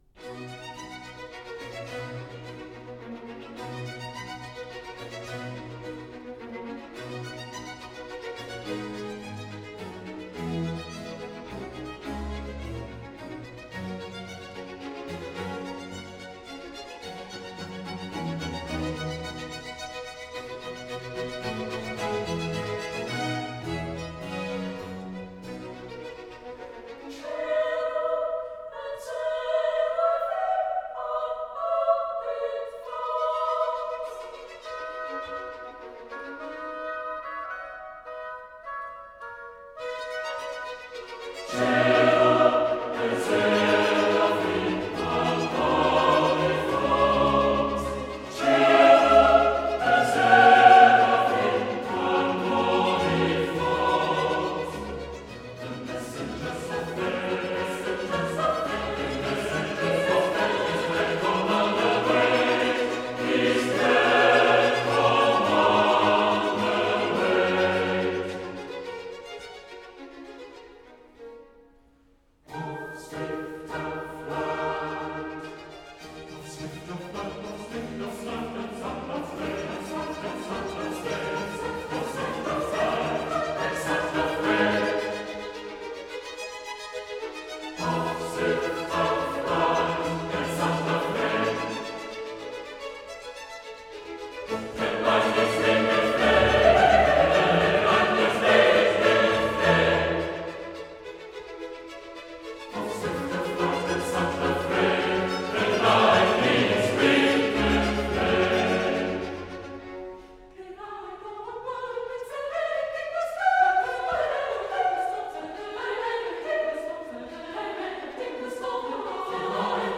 OLED SIIN ▶ muusika ▶ Klassika